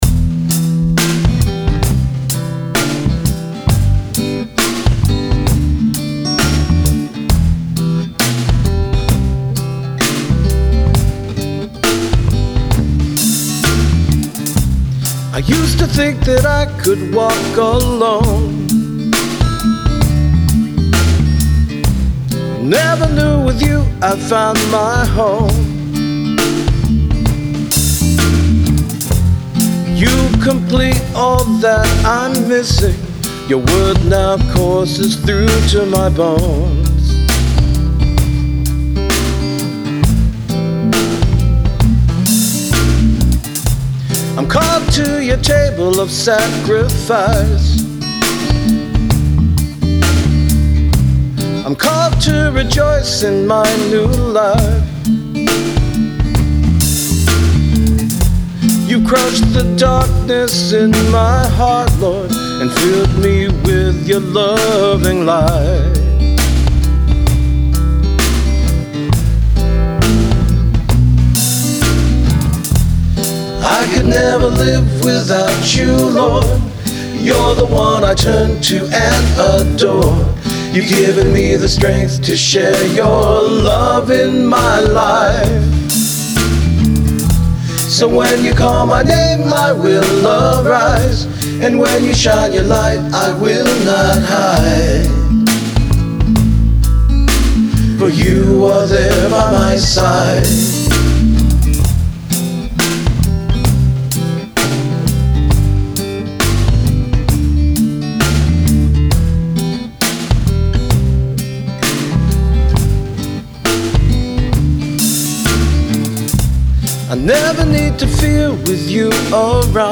So while I was jammin’ to a song in the car a few days ago, I got an idea for a praise and worship song with a Reggae theme. Here’s the initial sketch of the song (I have to add the rest of instrumentation, but here it is with just a three-piece combo arrangement):